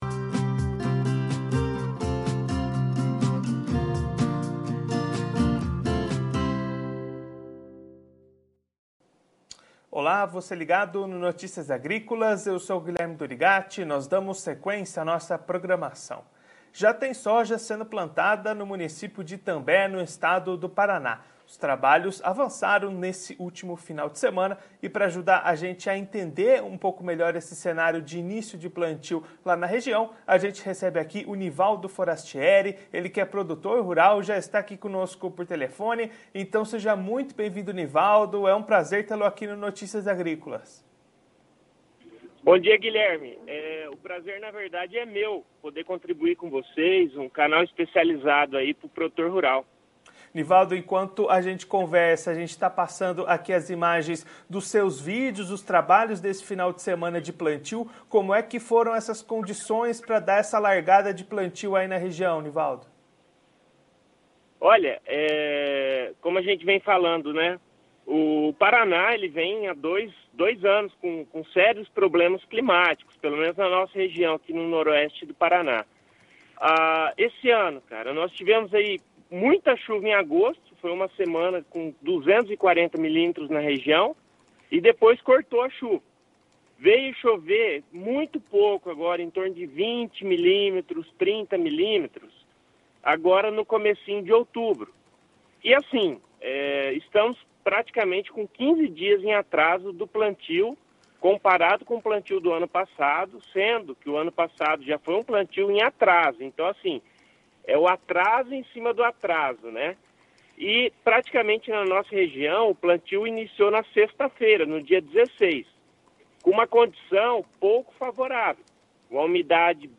Confira a entrevista completa com produtor rural de Itambé/PR no vídeo.